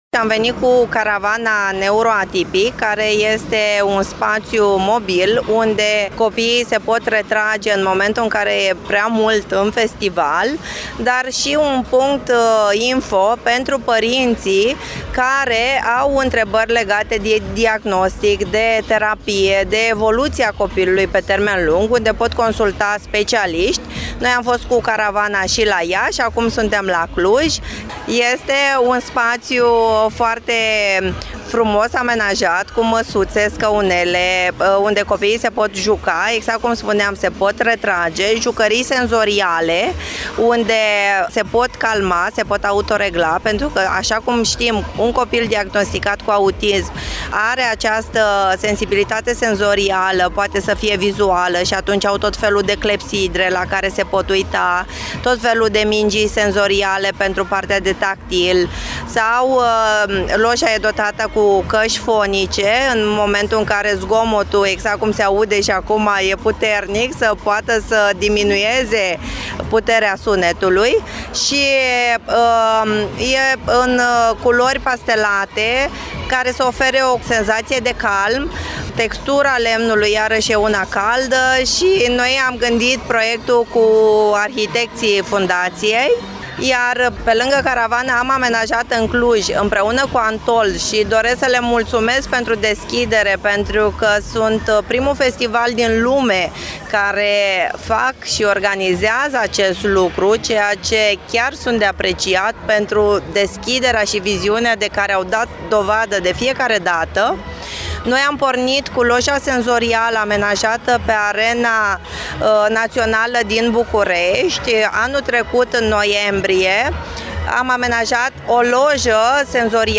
Prima pagină » Reportaje » Lojă, caravană și centru pentru tinerii cu autism | AUDIO